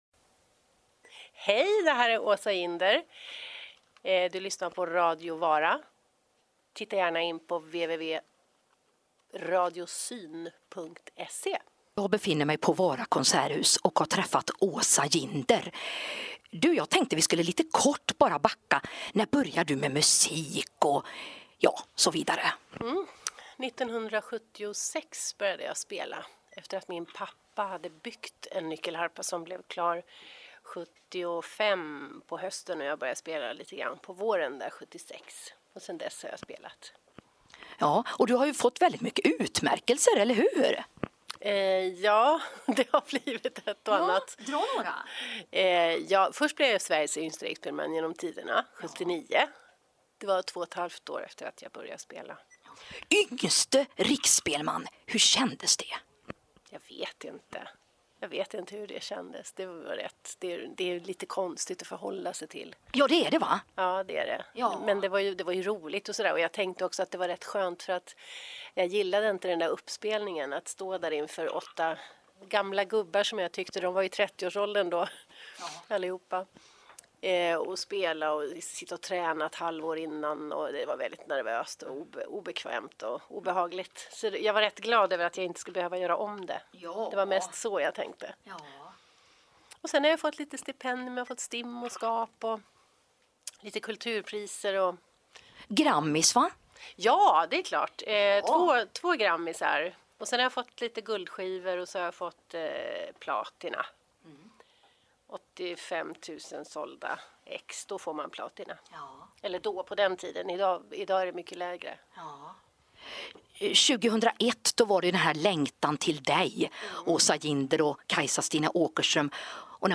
Åsa Jinder intervju.mp3
Asa Jinder intervju.mp3